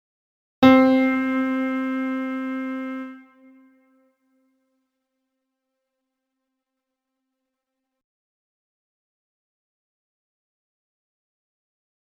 MIDDLE C is the C in the middle of the piano keyboard and also the C in between the 2 staves.